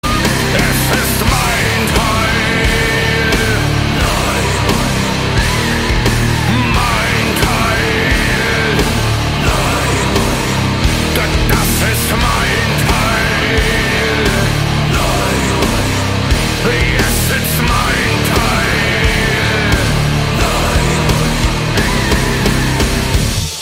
• Качество: 128, Stereo
жесткие
мощные
Industrial metal
Neue Deutsche Harte